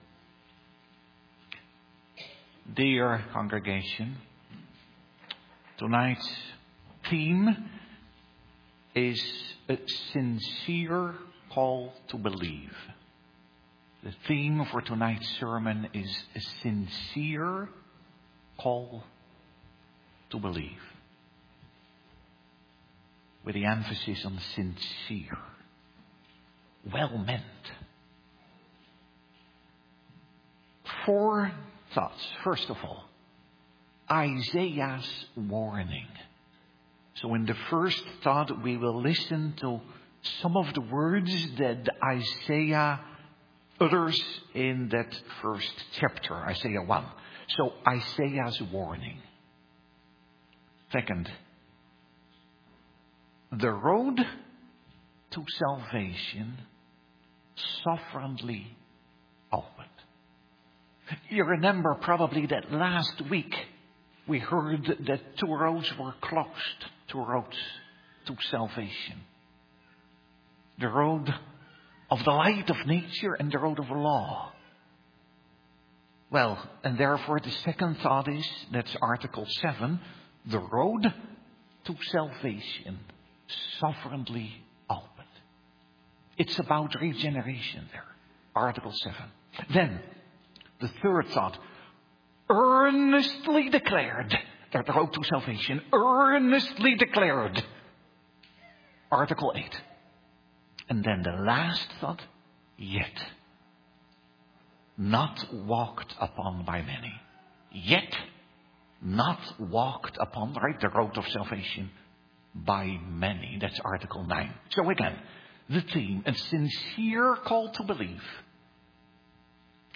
Sermon Audio | Providence Reformed Church
Download Download Reference Sunday Evening Service Theme: A Sincere Call to Believe Isaiah's mourning The road to salvation: Opened (Art 7) Earnestly declared (Art 8) Yet not waled upon my many.